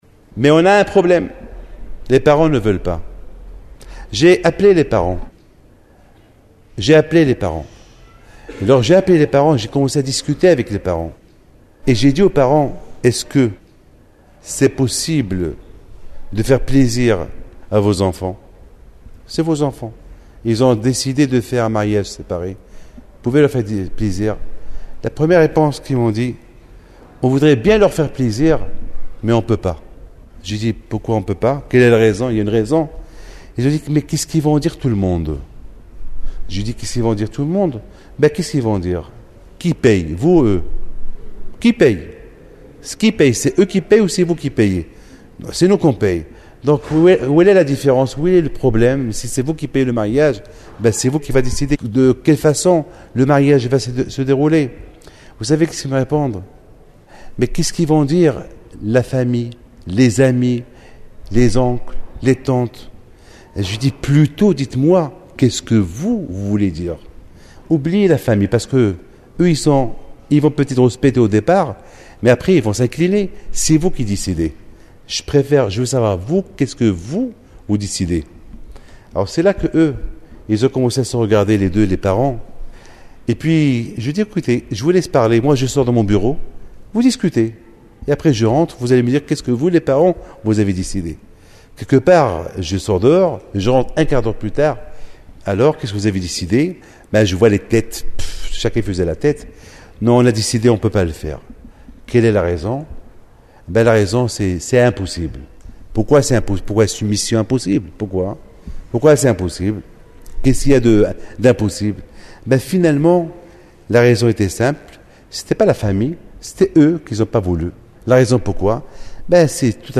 01:46:59 Nous sommes dans la grande synagogue de la rue Buffault à Paris le 14 Tamouz 5761 – 05 juillet 2001 à 3 jours des 3 semaines d’affliction qui marquent la destruction des 2 Temples de J rusalem et des exils qui ont suivi.